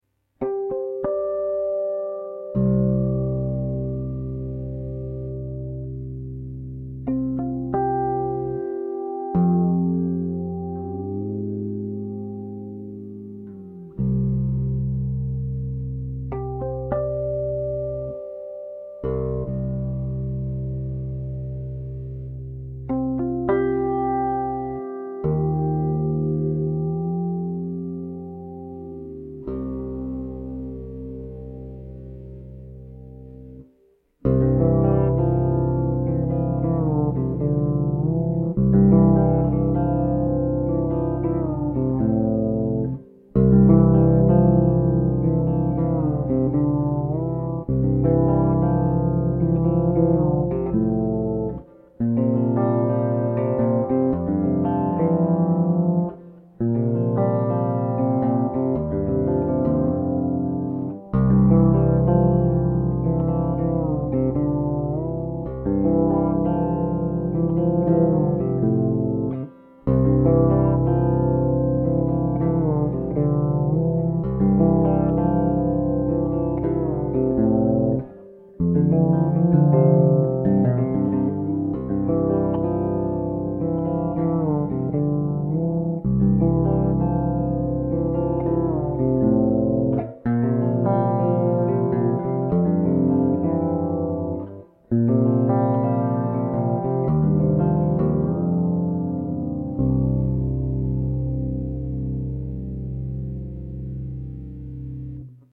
I've just recorded a simple demo of this Kronos fretless tuned CGCFGC, playing some sections from one of my original tunes.